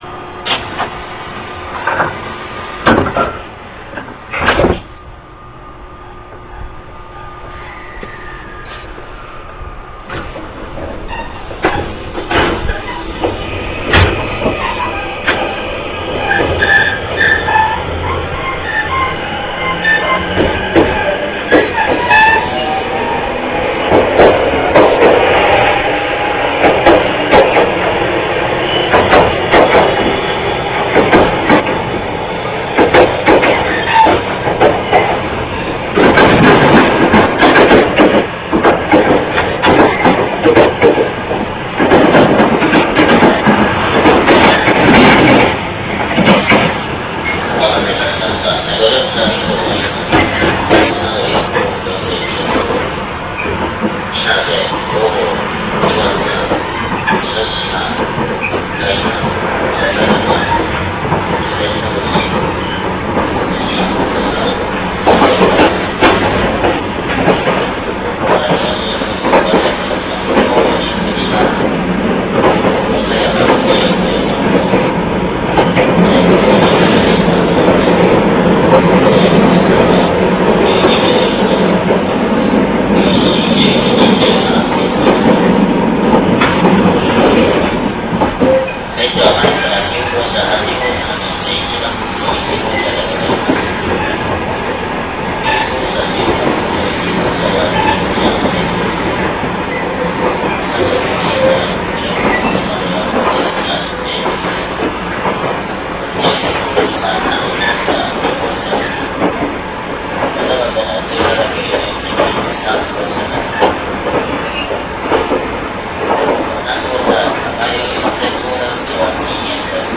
走行音(三菱)[283ma.ra/RA5.0-12Kbps Music/494KB]
制御方式：VVVFインバータ制御(三菱IGBT・個別制御)
この走行音はデッキでの収録 です。